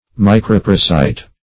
Search Result for " microparasite" : The Collaborative International Dictionary of English v.0.48: Microparasite \Mi`cro*par"a*site\, n. A parasitic microorganism.